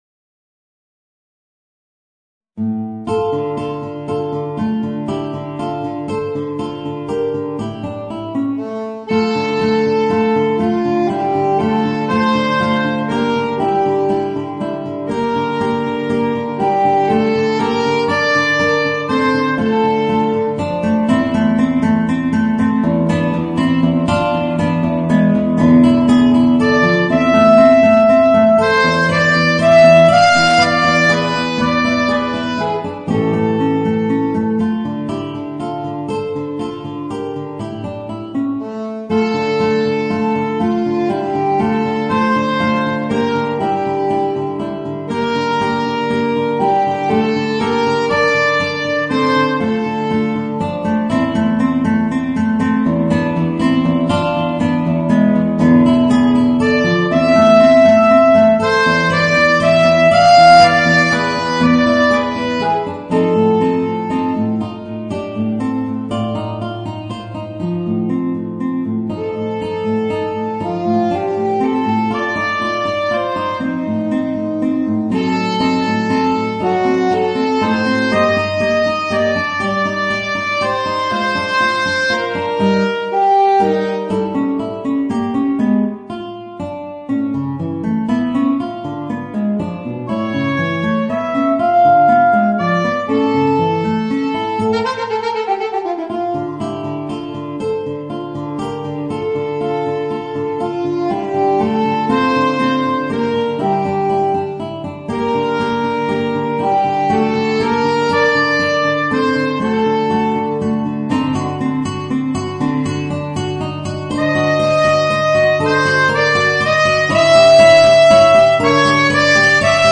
Voicing: Guitar and Alto Saxophone